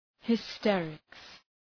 Προφορά
{hı’sterıks}